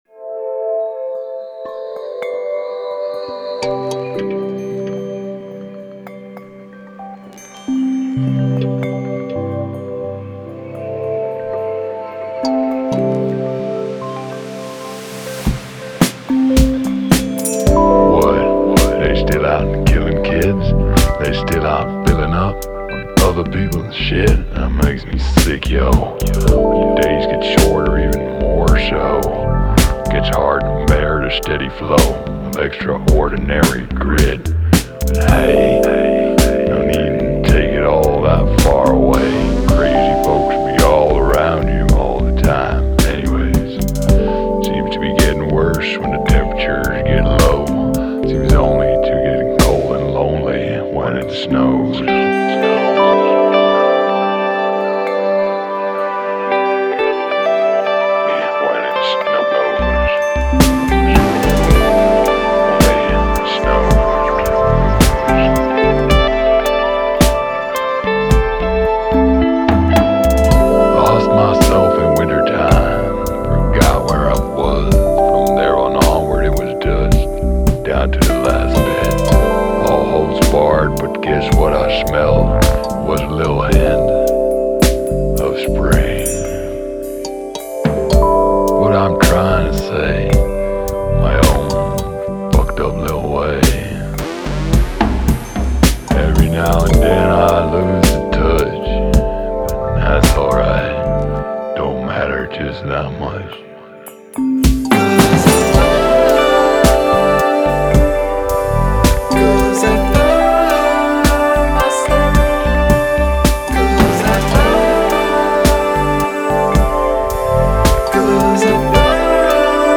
delicate selection of electric and acoustic instruments
cinematic downbeat downtempo dreamy